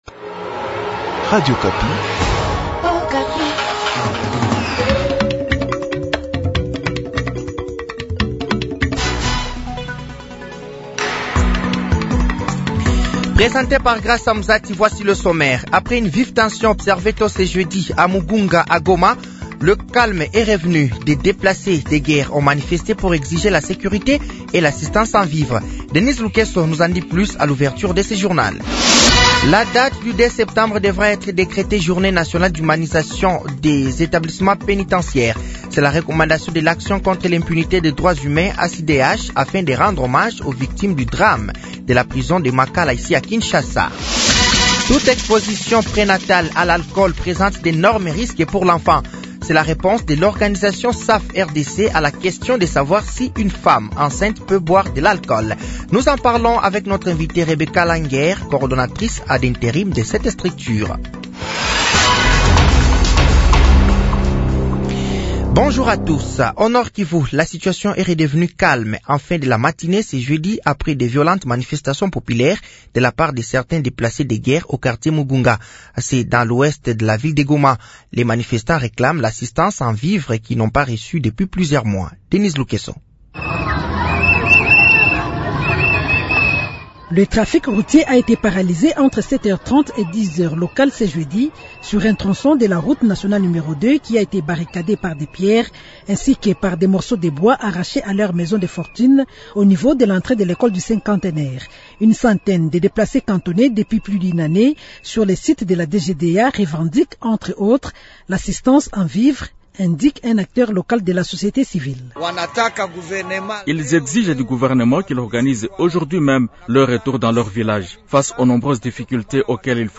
Journal français de 12h de ce jeudi 12 septembre 2024